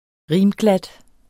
Udtale [ ˈʁiːm- ]